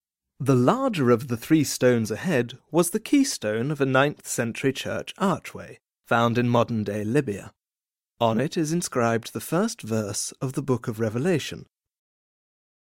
Native British, radio, video game experience
englisch (uk)
Sprechprobe: Sonstiges (Muttersprache):
I am a British actor, RP, experienced in voice over.